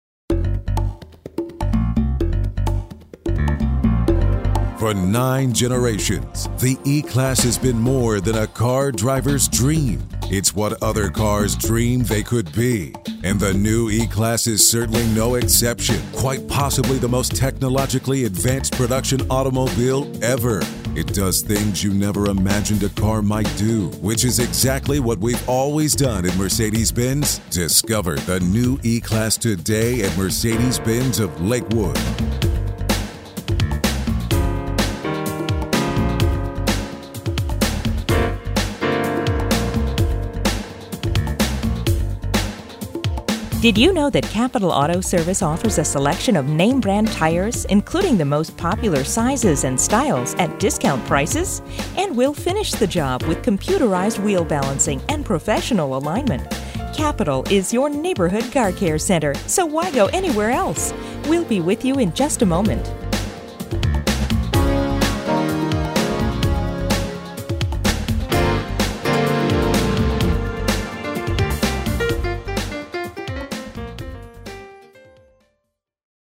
Messages on hold
Music on hold